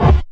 Campfire Kick.wav